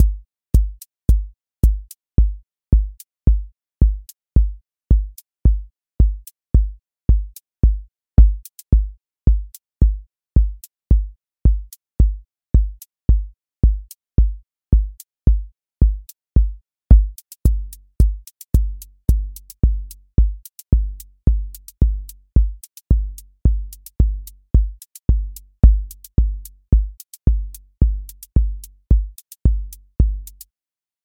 QA Test — Four on Floor